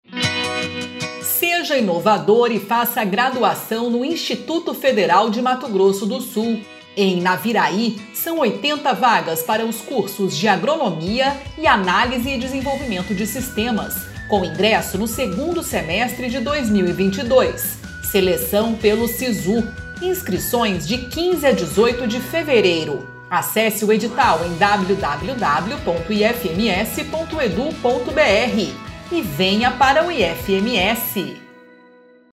Spot - Cursos de graduação para o 2º semestre de 2022 em Naviraí